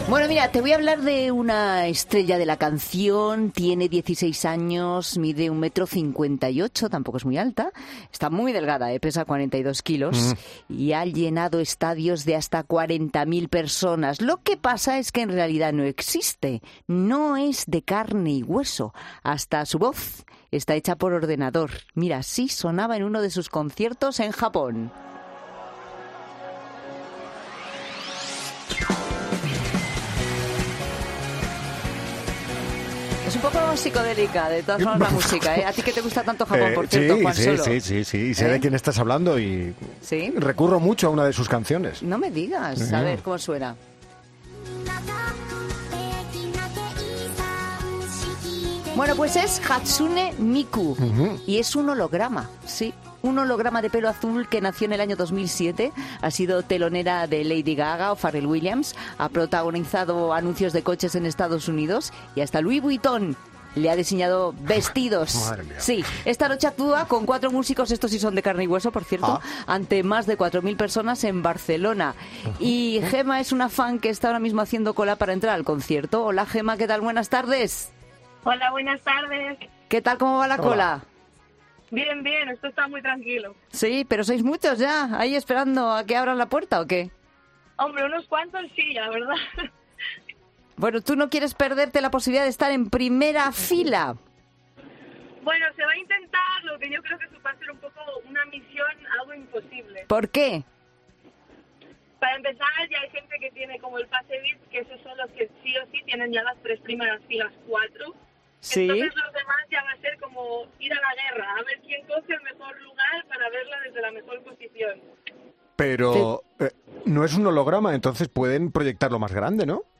Y en 'La Tarde' de COPE, hemos podido hablar con una de sus seguidoras que tiene su entrada para ver a Miku en directo.